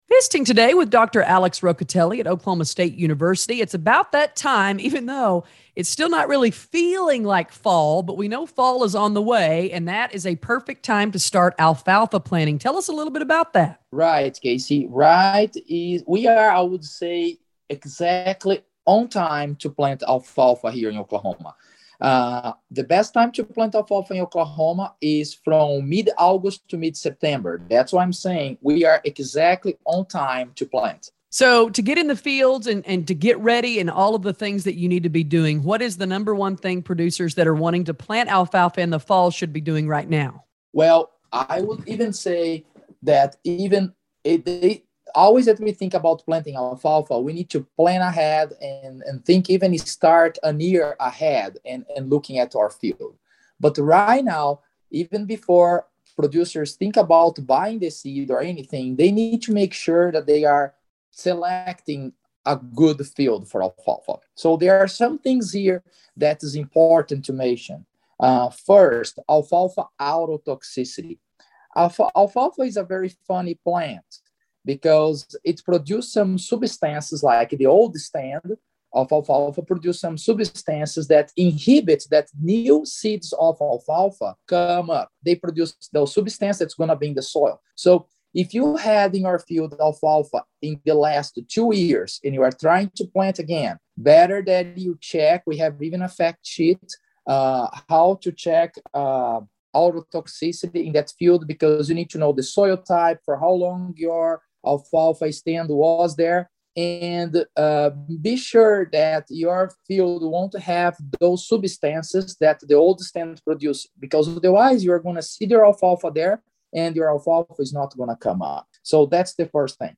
Radio Oklahoma Network keeps producers updated with ag news, reports, markets, sales and so much more!